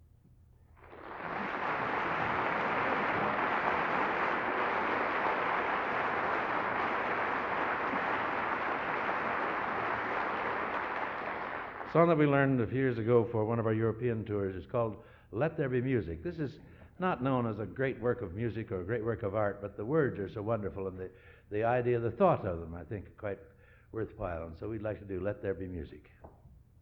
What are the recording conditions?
Collection: End of Season, 1969